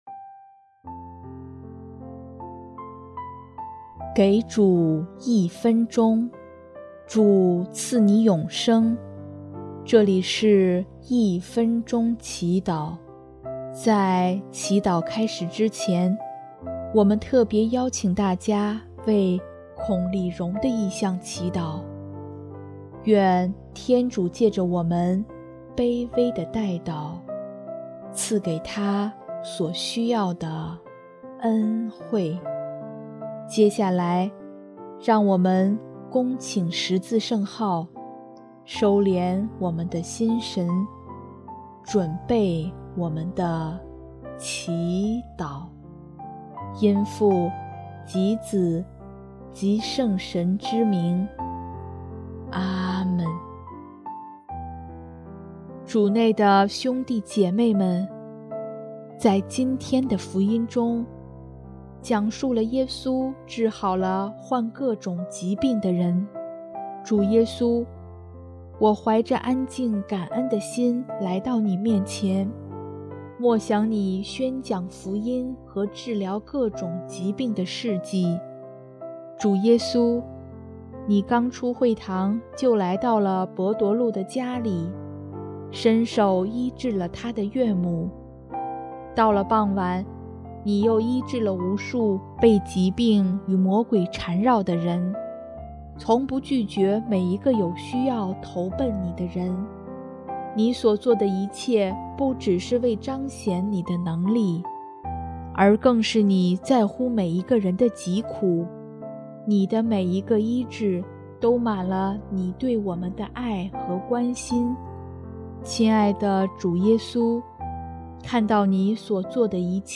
【一分钟祈祷】|1月14日 领受爱传递爱